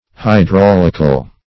Hydraulical \Hy*drau"lic*al\, a.